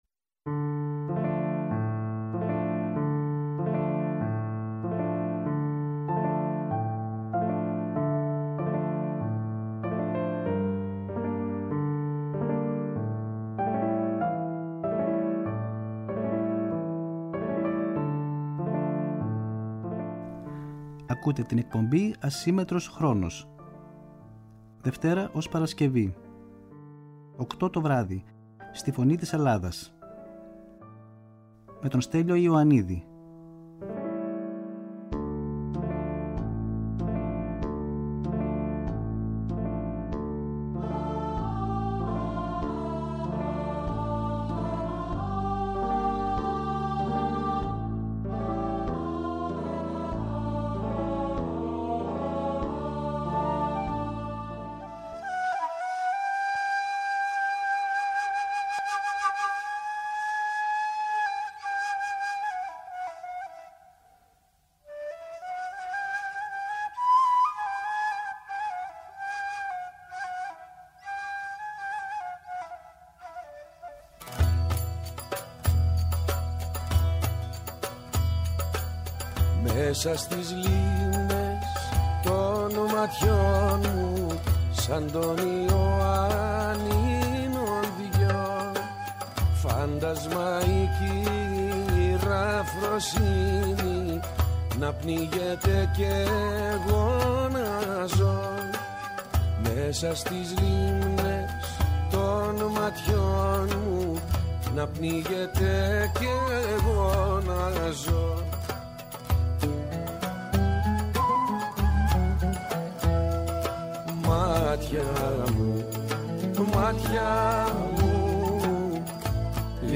Μια εκπομπή με τραγούδια που αγαπήσαμε, μελωδίες που ξυπνούν μνήμες, αφιερώματα σε σημαντικούς δημιουργούς, κυρίως της ελληνικής μουσικής σκηνής, ενώ δεν απουσιάζουν οι εκφραστές της jazz και του παγκόσμιου μουσικού πολιτισμού.